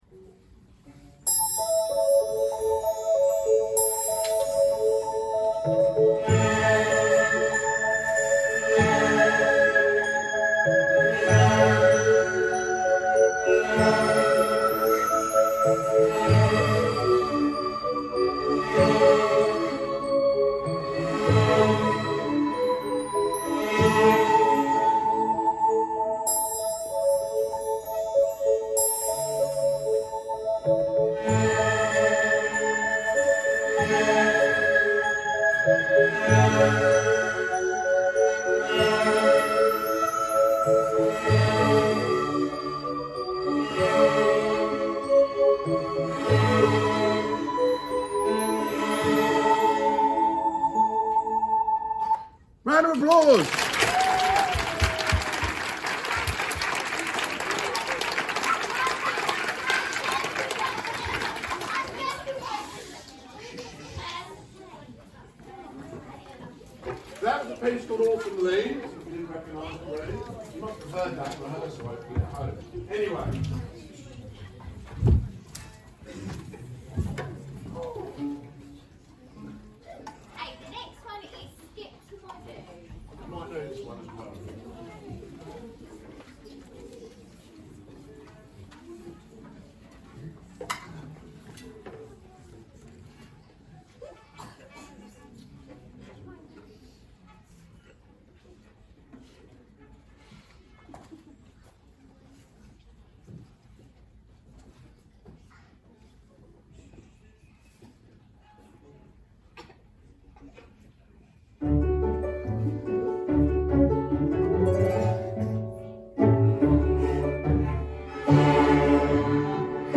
Year 2 - Strings Training Programme Celebration - March 2023